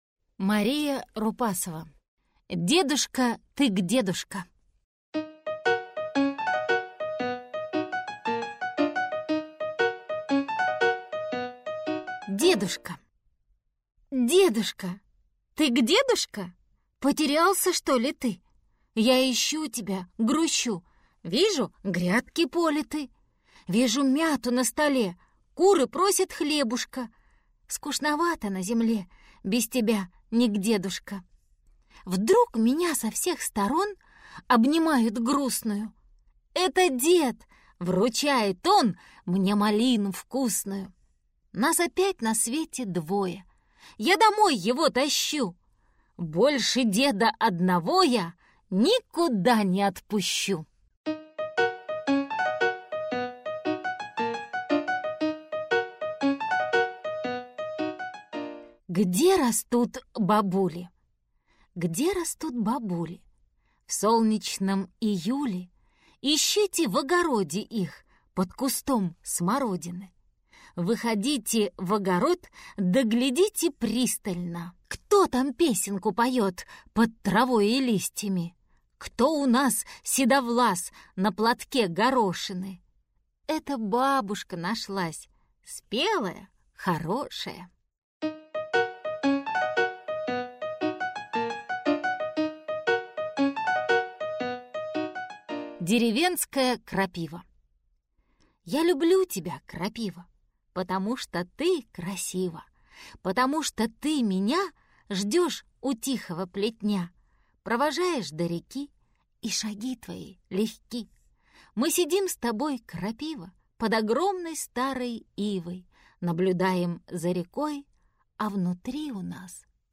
Аудиокнига Дедушка, ты гдедушка?